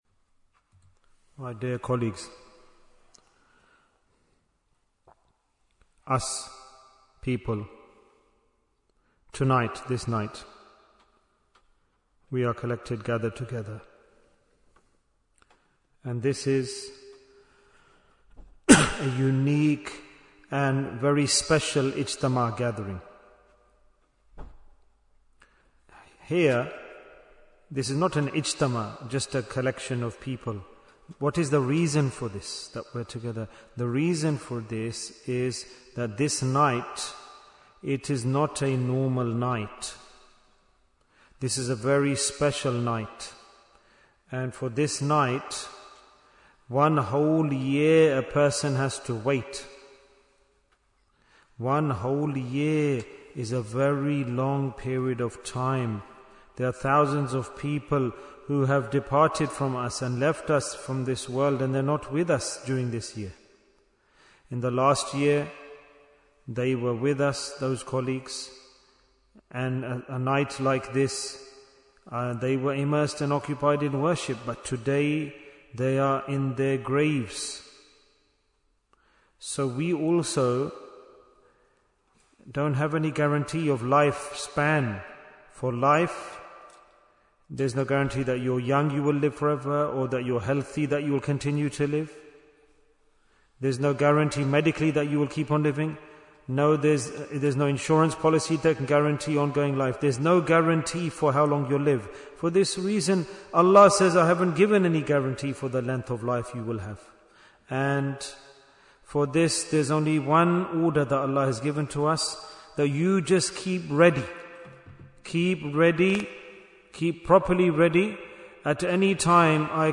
Jewels of Ramadhan 2025 - Episode 35 - The Last Asharah in Bradford Bayan, 64 minutes24th March, 2025